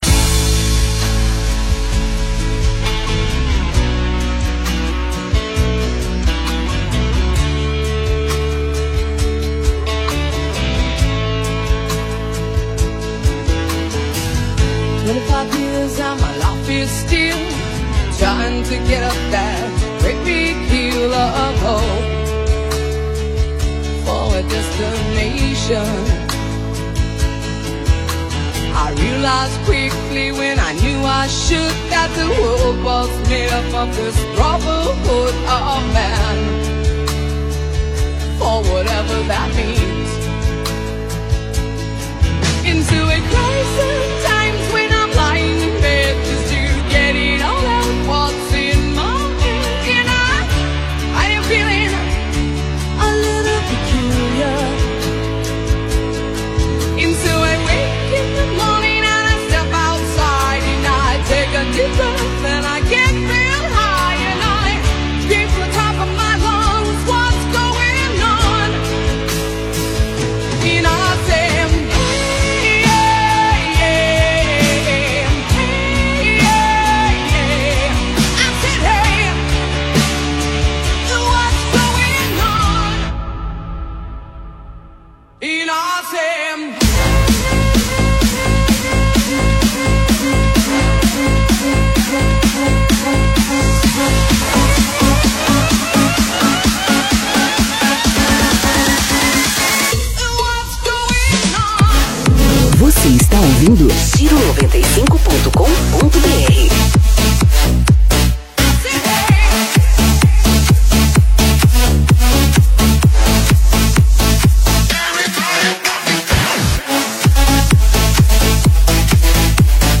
FlashBack Remix